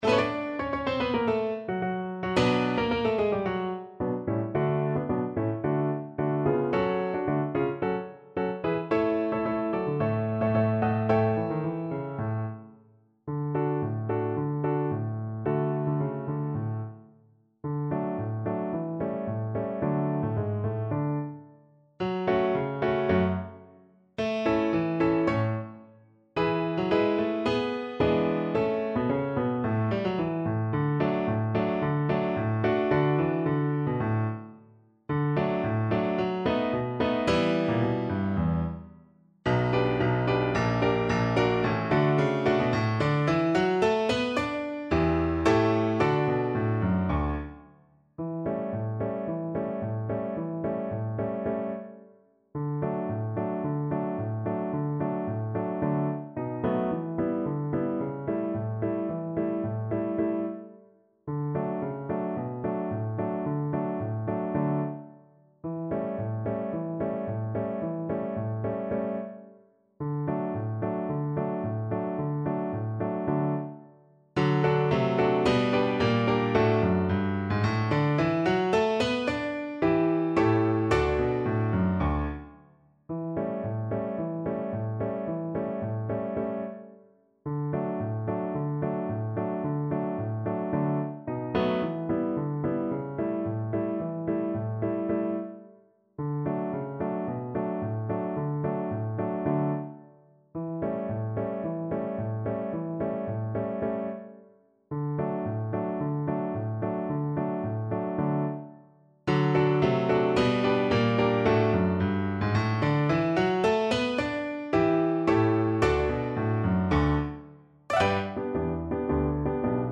2/2 (View more 2/2 Music)
Classical (View more Classical Violin Music)